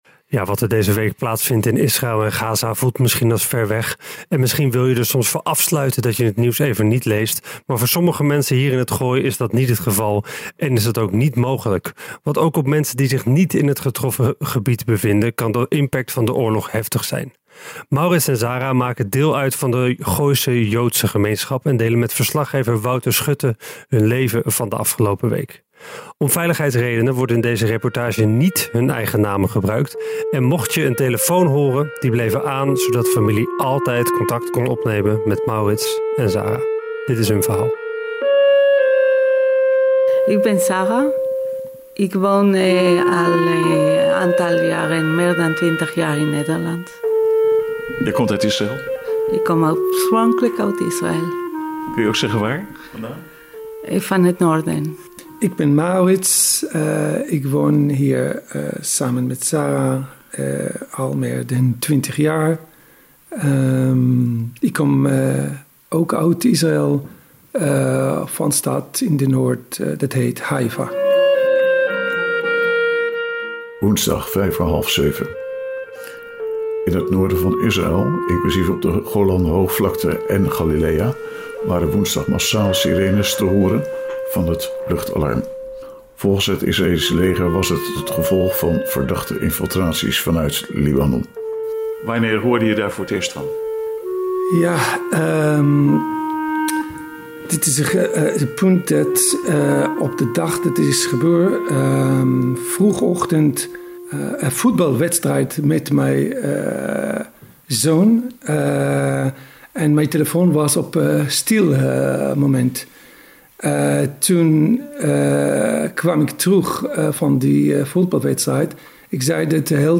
En wat doet het met hun kinderen. We luisteren nog even naar de uitzending van toen.